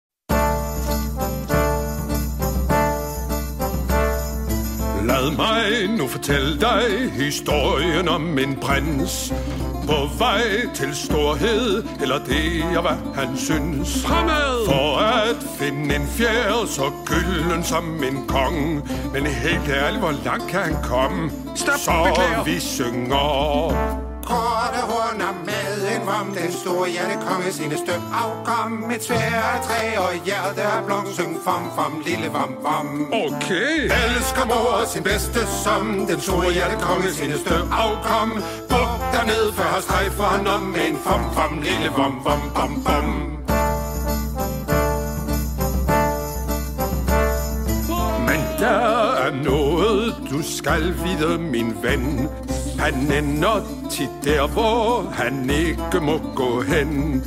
Deep, warm voice.
Singing